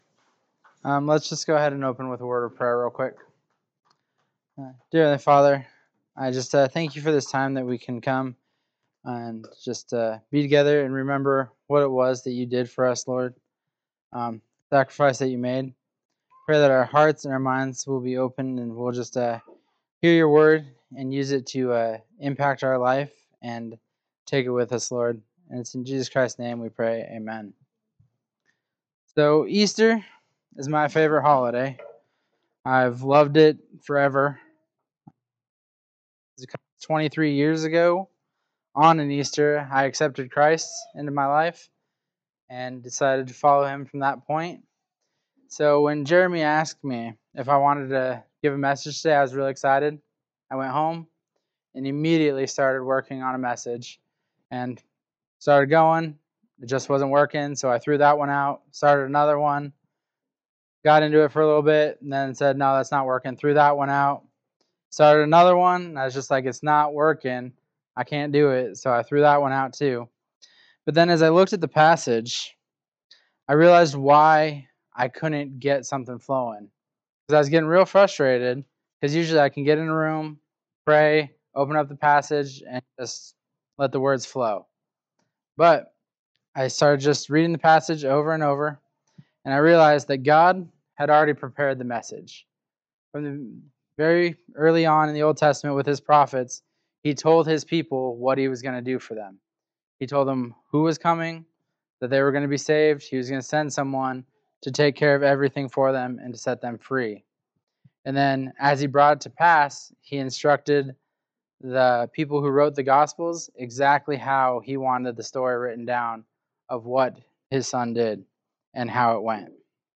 Easter SonRise Service